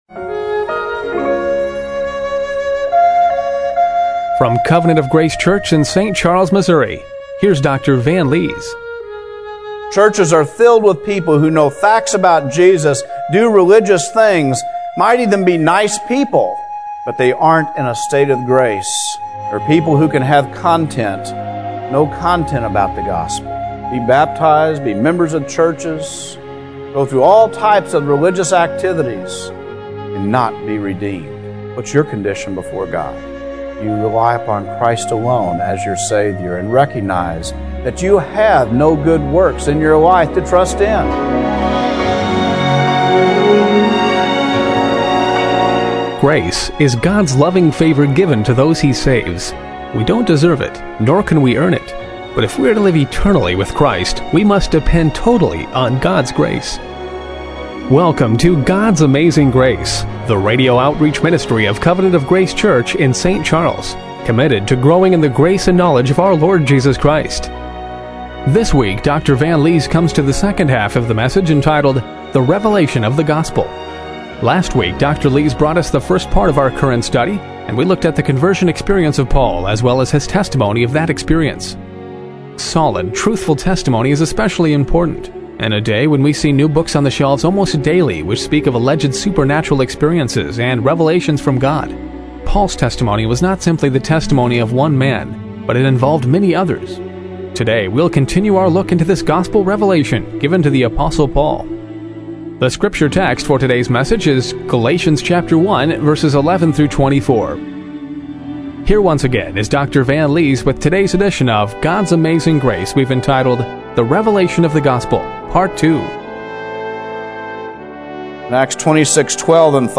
Galatians 1:11-24 Service Type: Radio Broadcast Continuing our look into the Gospel revelation given to the Apostle Paul.